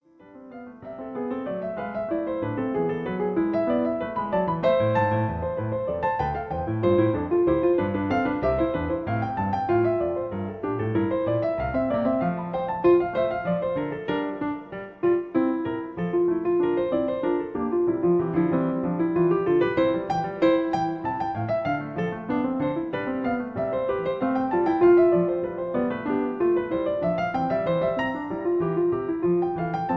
Original Key. Piano Accompaniment